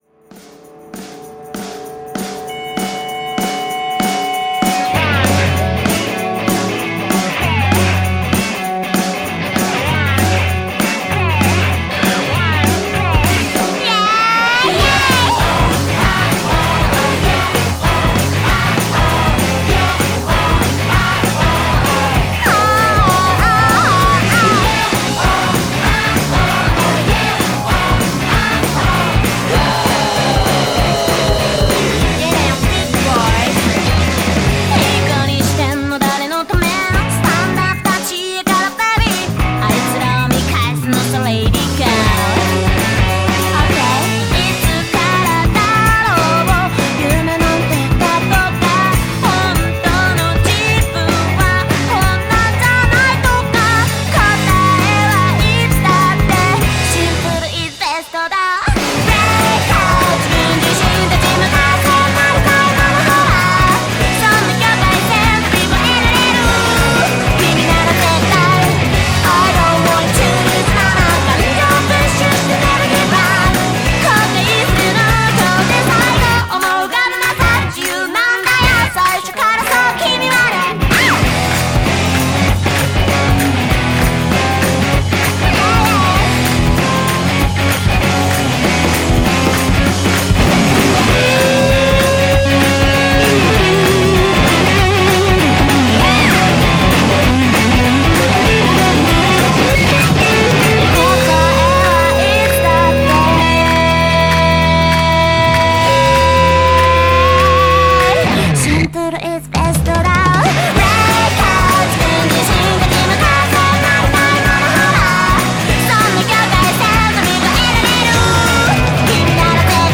BPM195
MP3 QualityMusic Cut